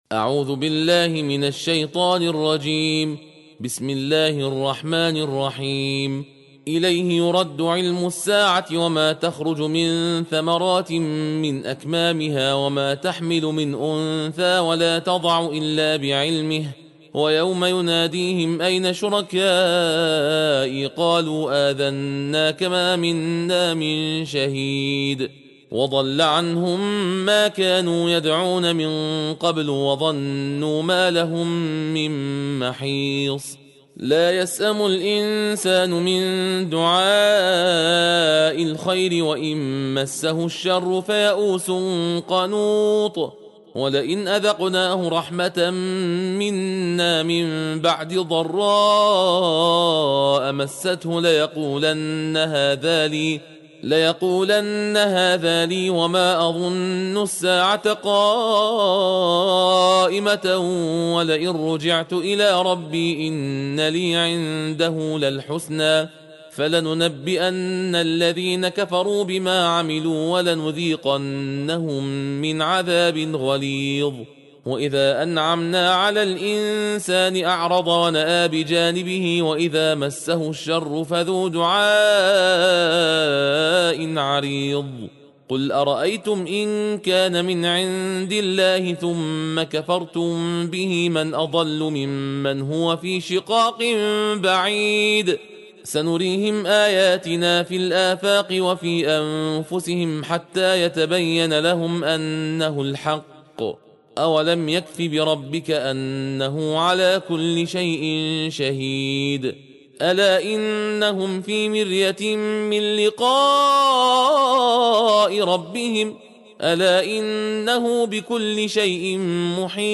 برای ختم بیست و پنجمین جز با مطالعه و گوش سپردن به تندخوانی سوره هاي جز 25 قرآن، همراه ما باشید.